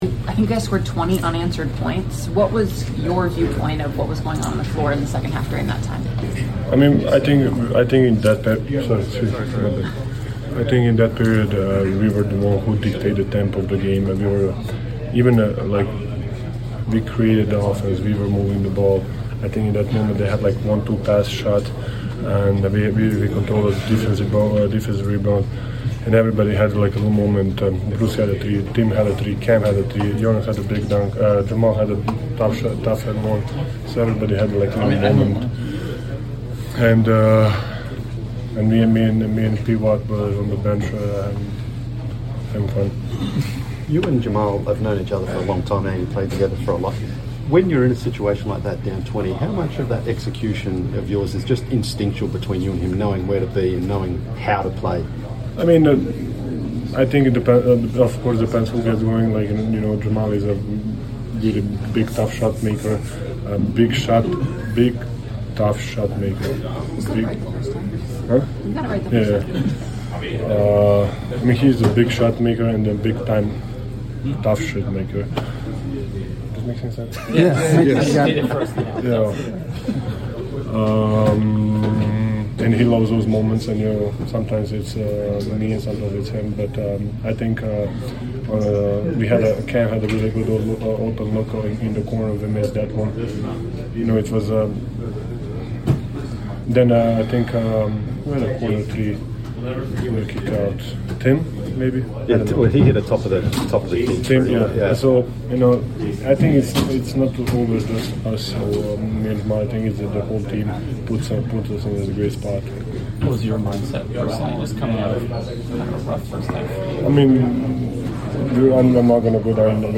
Denver Nuggets Center Nikola Jokić Postgame Interview after defeating the Atlanta Hawks at State Farm Arena.